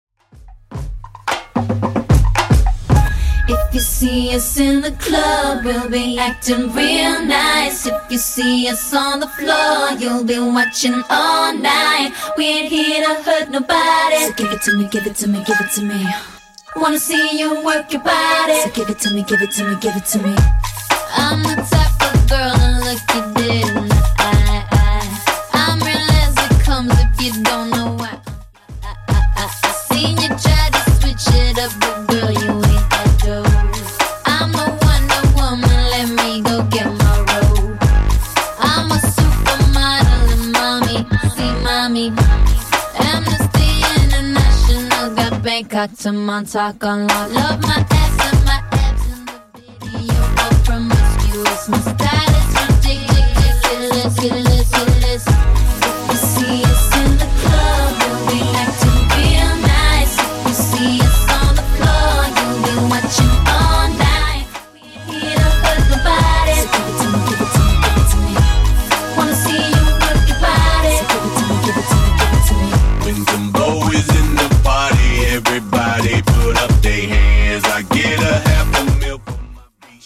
Genres: RE-DRUM , TOP40
BPM: 76